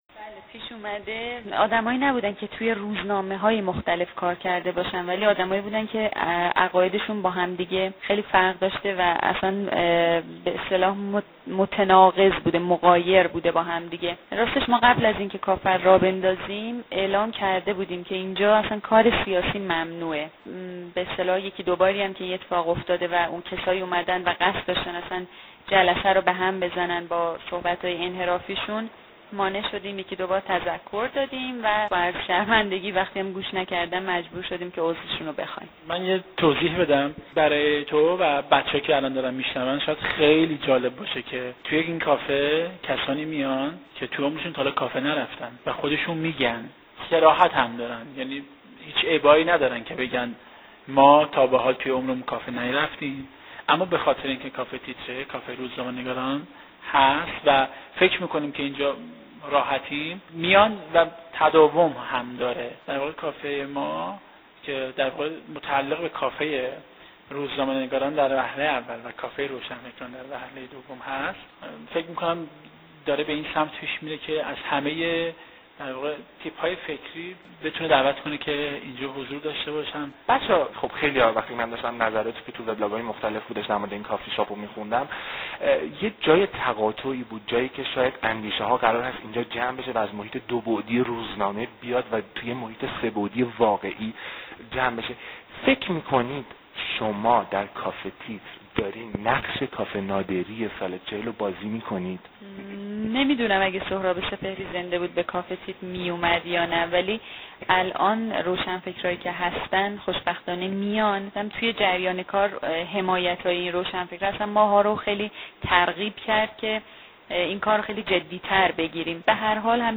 گوش کنین محیطش چطوریه ، ماهم همینو می خوایم ولی یکخورده ...؟ تر.